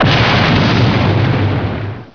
LightningPower.wav